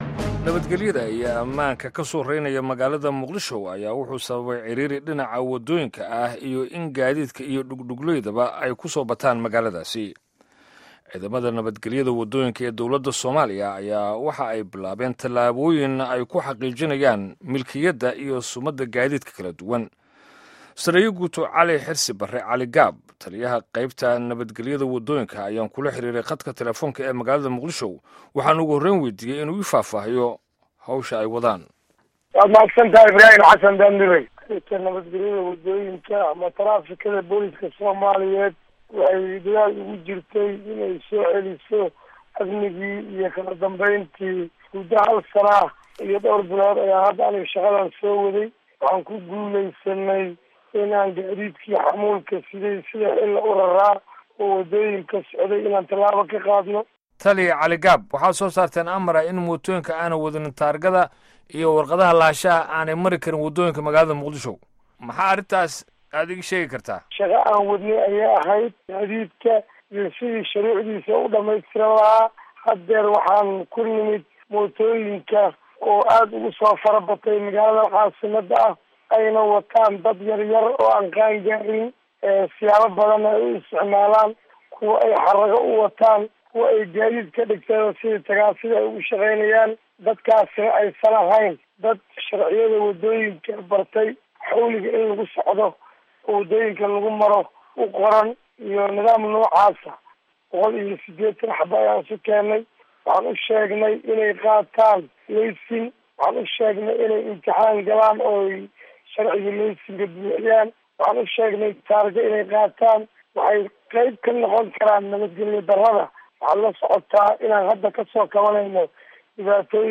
Wareysiga Taraafikada Muqdisho.